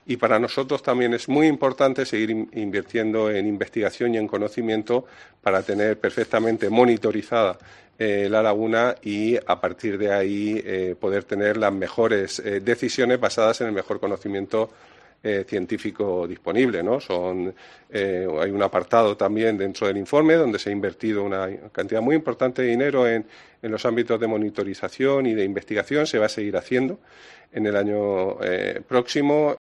Juan María Vázquez, consejero de Medio Ambiente, Universidades, Investigación y Mar Menor